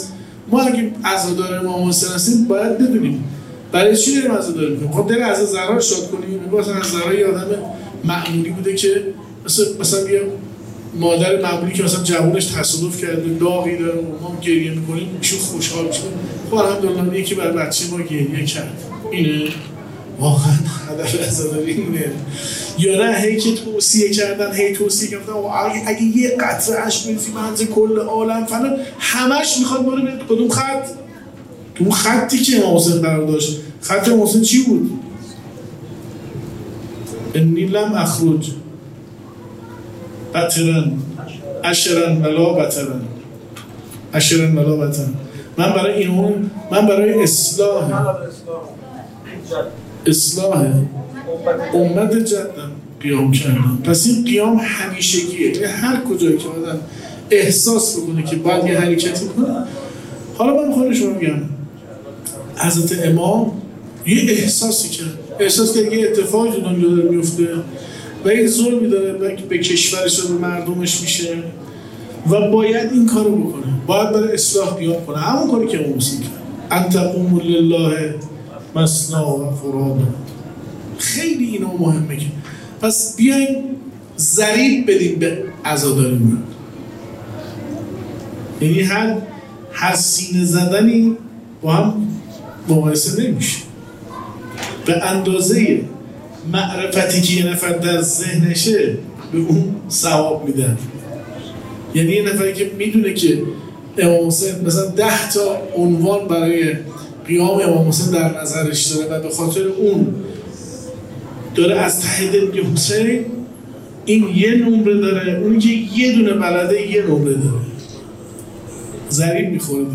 بیست و چهارمین محفل از سلسله محافل منادی همزمان با افتتاح دارالقرآن آستان مقدس امامزاده عقیل‌(ع) با حضور قاریان، حافظان و پیشکسوتان قرآنی و علاقه‌مندان به ساحت مقدس قرآن برگزار شد.